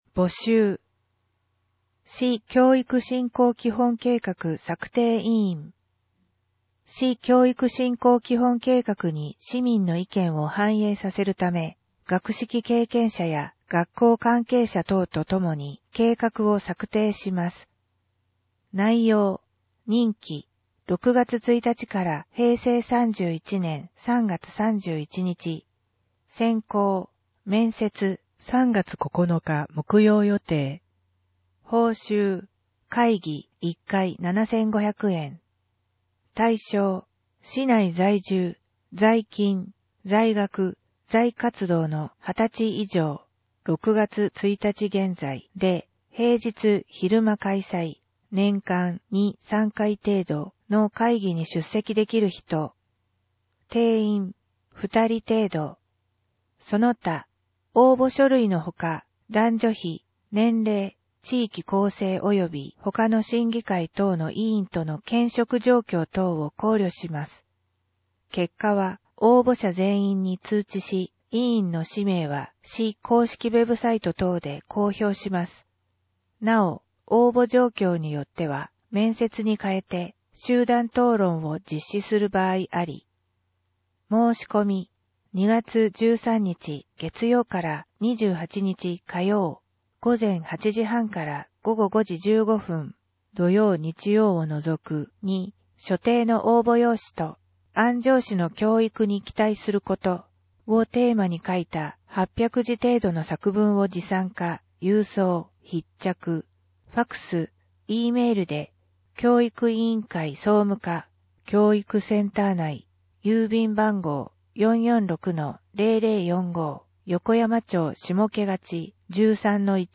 広報あんじょうの音声版を公開しています
なお、以上の音声データは、「音訳ボランティア安城ひびきの会」の協力で作成しています。